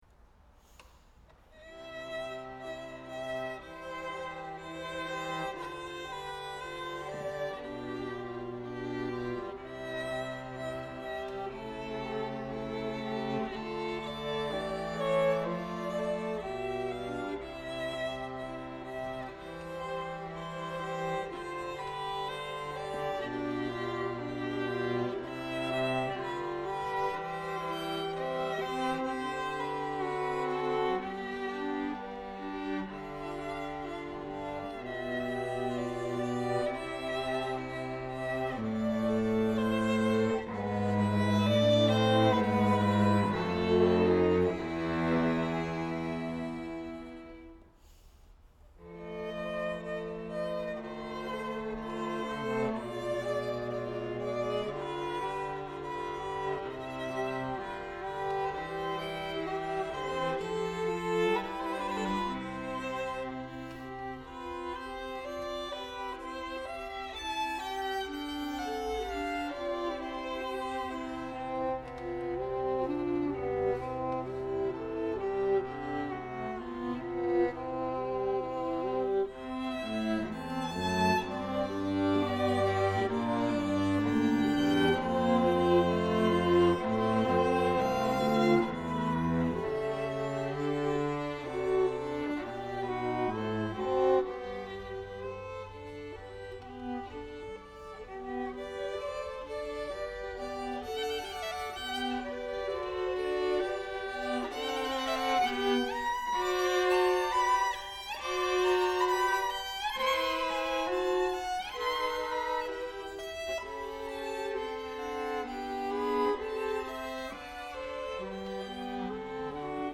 Chamber Groups
Andante